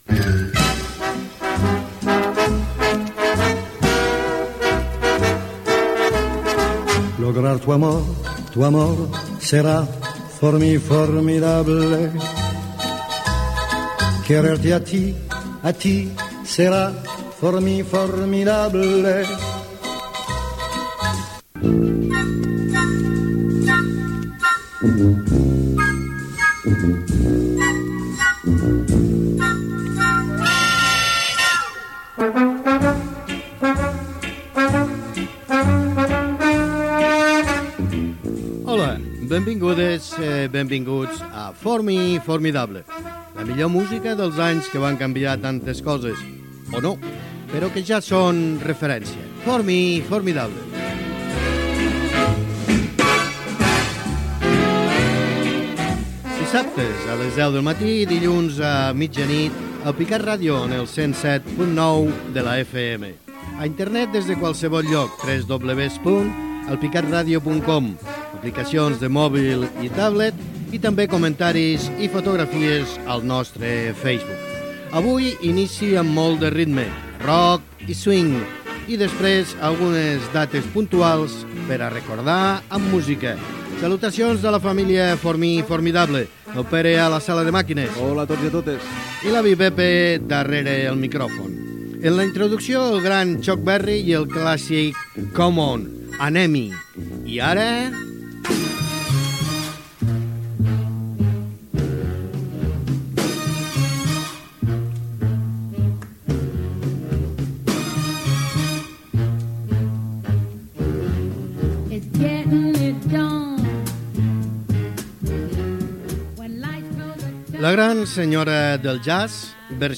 Tema musical, presentació, llocs per on es pot escoltar el programa, equip, tema musical
Musical